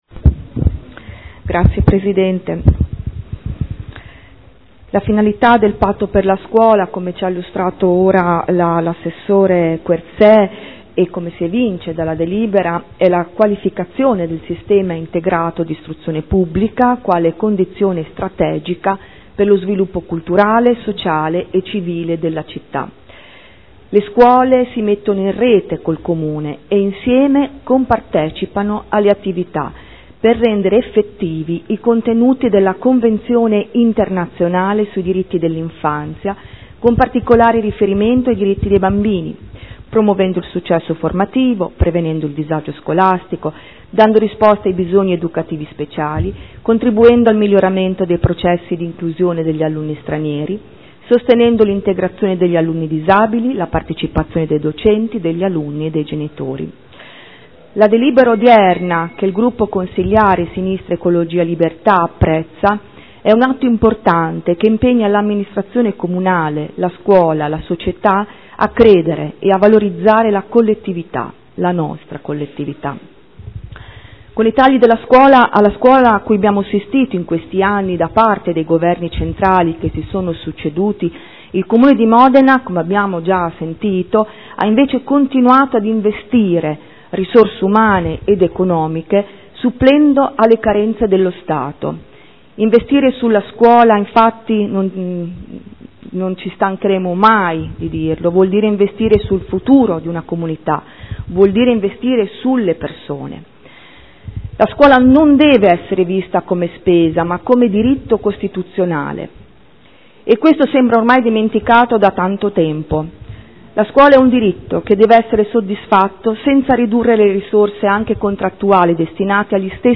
Seduta del 12/09/2013 Approvazione “Patto per la scuola” tra Comune di Modena e scuole statali primarie e secondarie di primo grado